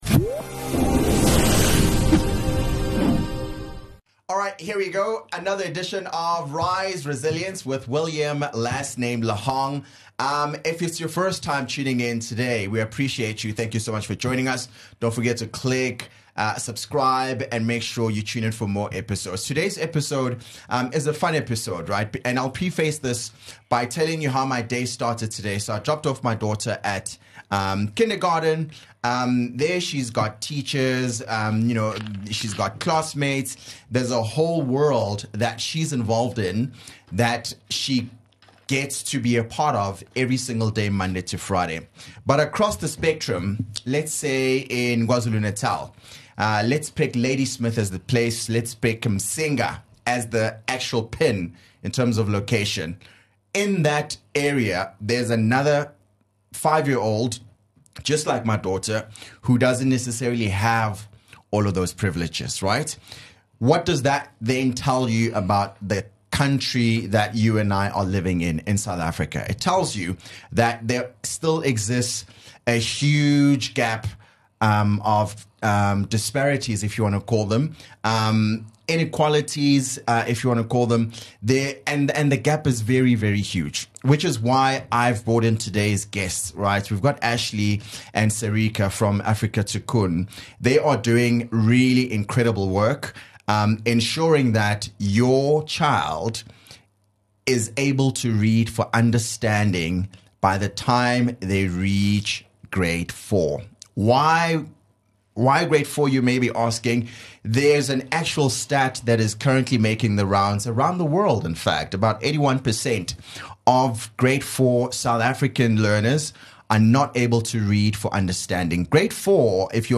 Joining him in studio to unpack this discussion are two women who have made it their business to educate today's youth.